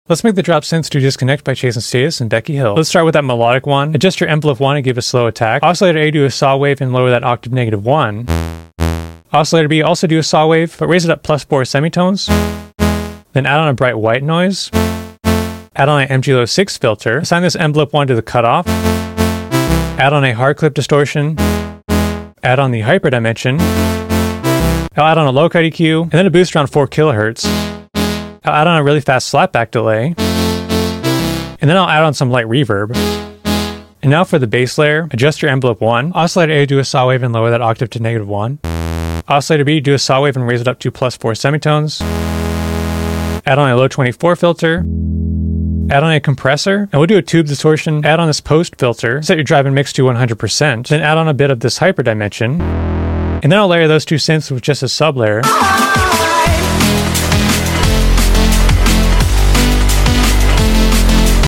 Drop Synths in Serum
synth, sound design, tutorial, re-make